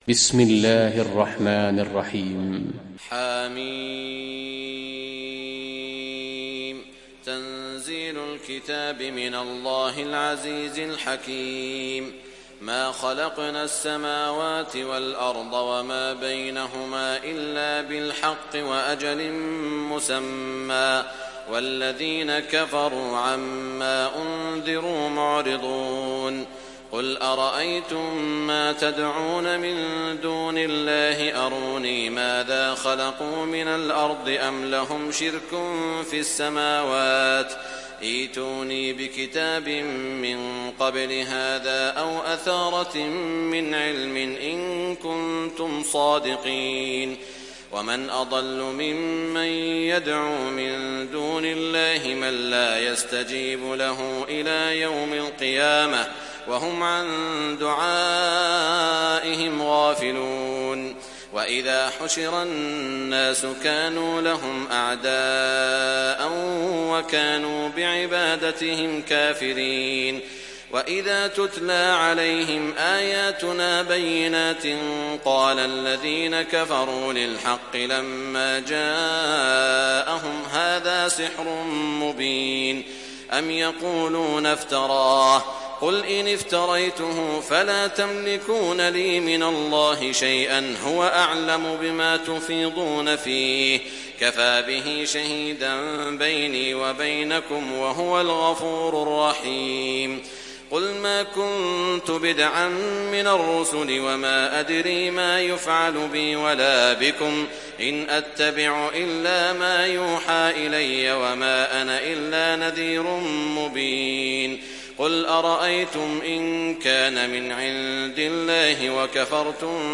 تحميل سورة الأحقاف mp3 بصوت سعود الشريم برواية حفص عن عاصم, تحميل استماع القرآن الكريم على الجوال mp3 كاملا بروابط مباشرة وسريعة